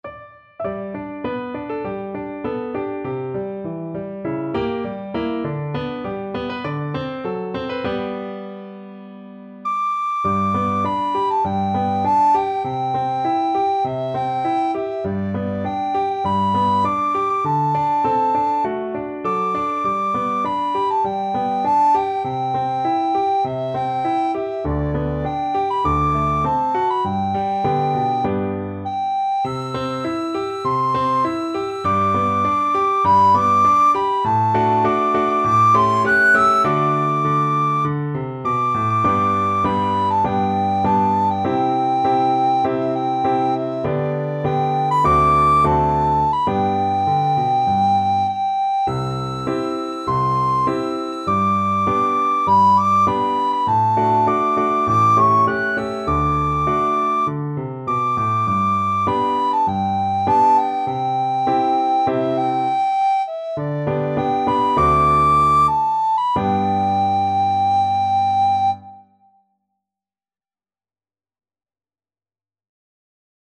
Traditional Trad. When You And I Were Young, Maggie Soprano (Descant) Recorder version
Recorder
4/4 (View more 4/4 Music)
G major (Sounding Pitch) (View more G major Music for Recorder )
~ = 100 Moderato